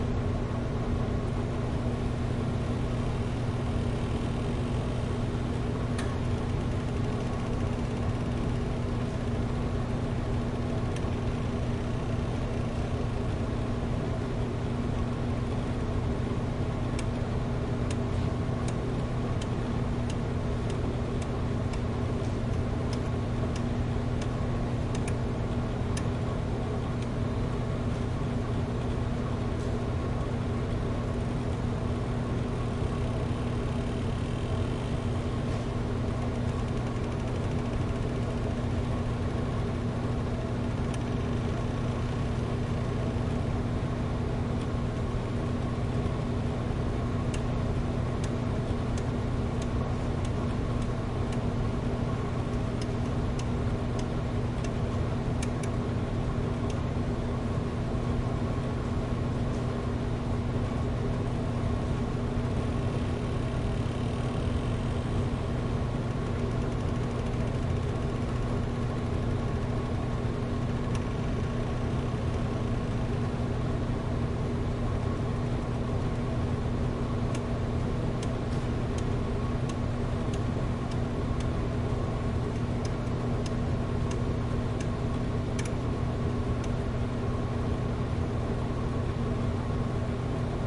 laundromat » laundromat washer washing machine rumble1 clicks
描述：laundromat washer washing machine rumble1 clicks.flac
标签： washing laundromat washer rumble machine
声道立体声